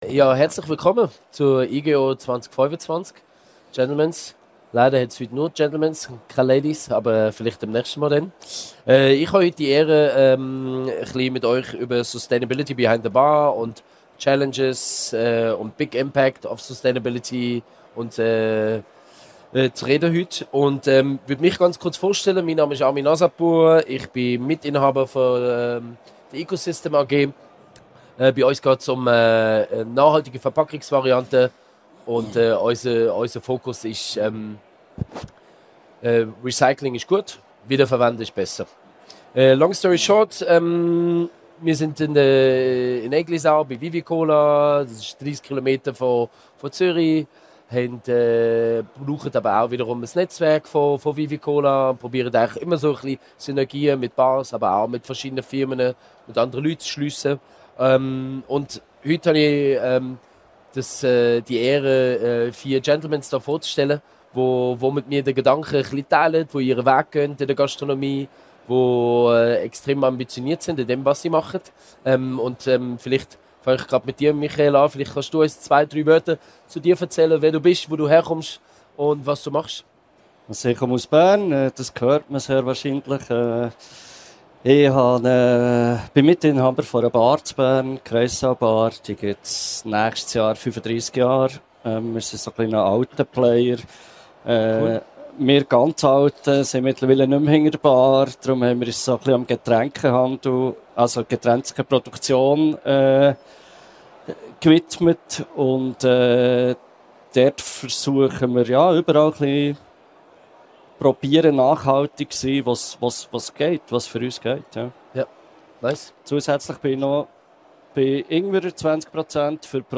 In diesem Podiumsgespräch trafen Barbetreiber sowie Zulieferer aus dem In- und Ausland aufeinander, um gemeinsam zu diskutieren, wie ein bewussterer Umgang mit Ressourcen gelingen kann. Im Mittelpunkt stand die Frage, wie Bars ihren Betrieb nachhaltiger gestalten können – von regionalen Produkten und saisonalen Zutaten bis hin zu Abfallvermeidung und Kreisläufen. Das Podiumsgespräch wurde am 16. November 2025 auf der Bühne der Sonderschau Barbesuch der Igeho 2025 aufgezeichnet.